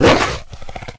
pdog_threaten_0.ogg